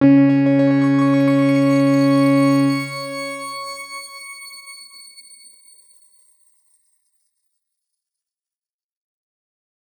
X_Grain-C#3-ff.wav